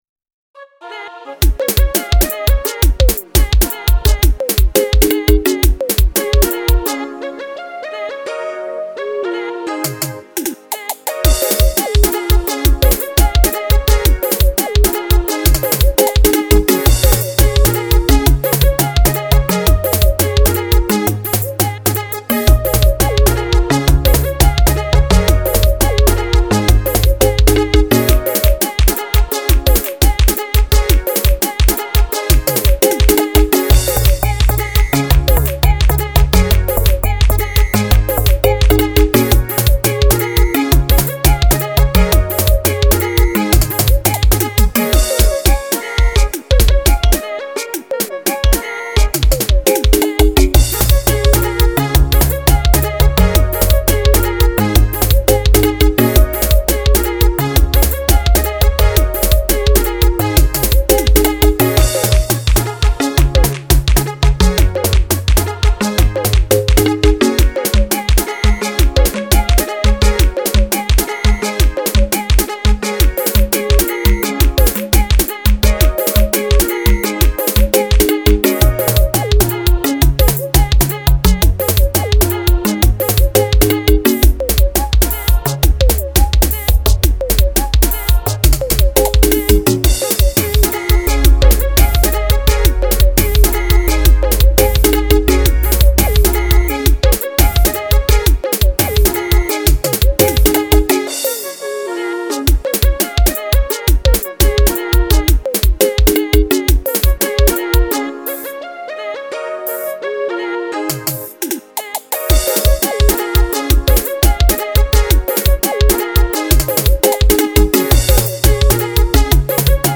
03:30 Genre : Xitsonga Size